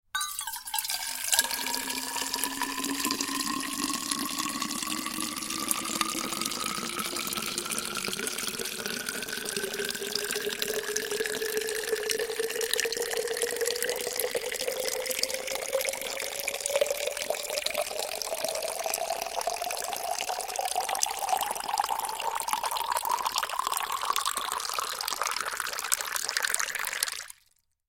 Звук наливания кипятка из термоса